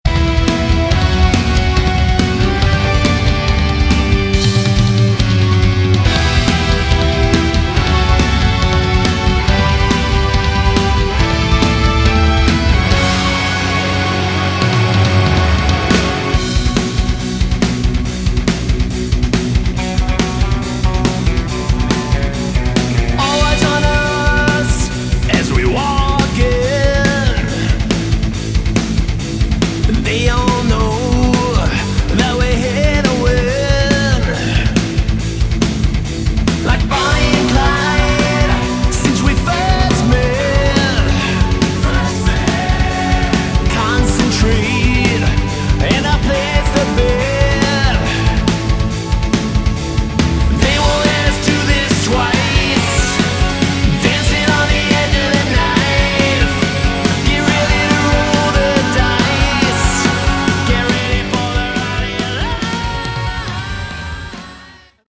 Category: Melodic Hard Rock
vocals
bass
guitars
keyboards
drums